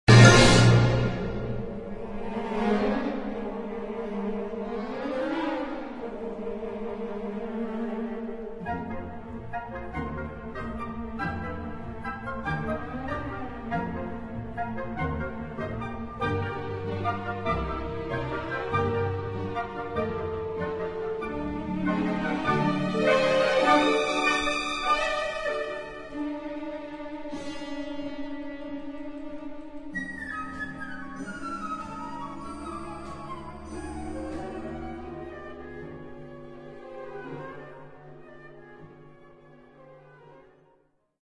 Lent 2:23